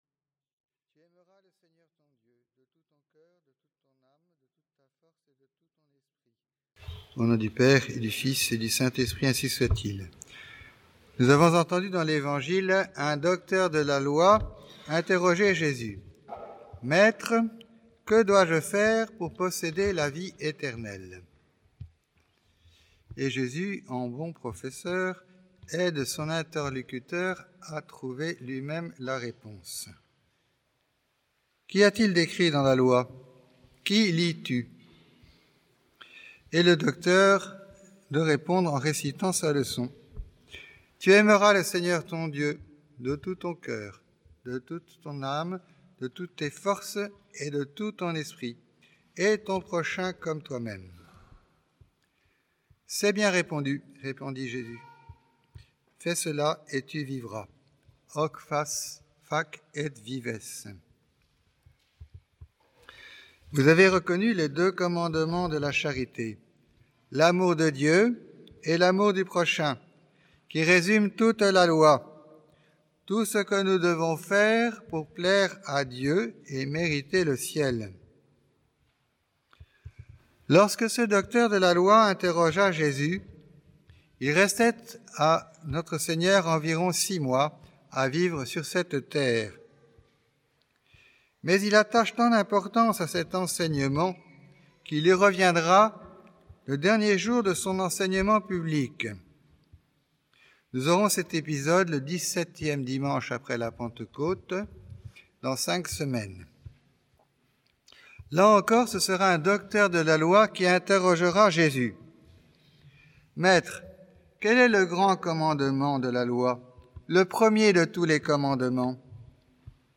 Occasion: Douzième dimanche après la Pentecôte
Type: Sermons